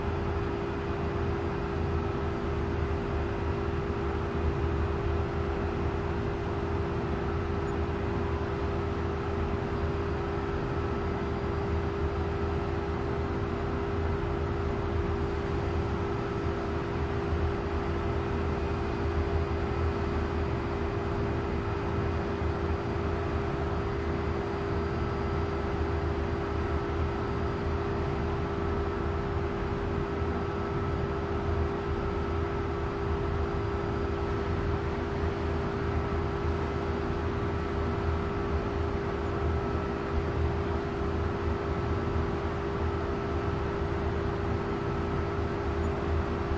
随机 " 嗡嗡声工业水处理厂2
描述：嗡嗡声工业水处理plant2.flac
标签： 处理 工业 植物 哼唱
声道立体声